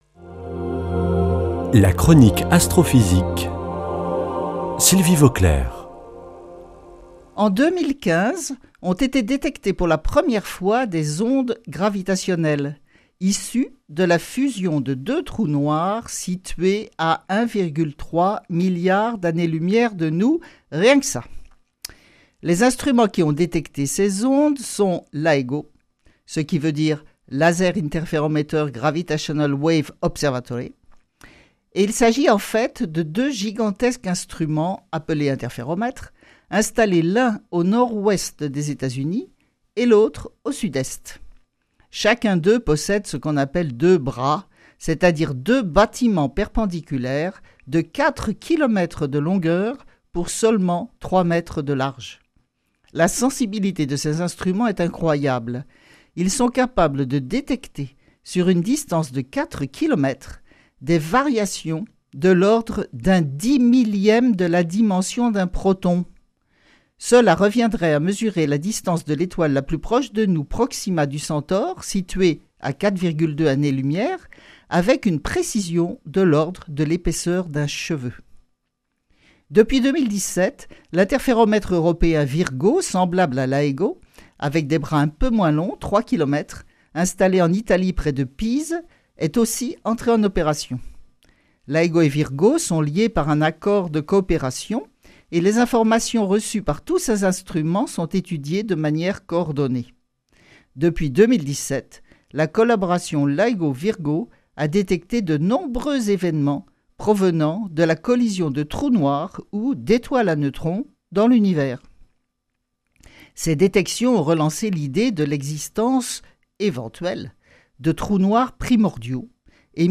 Astrophysicienne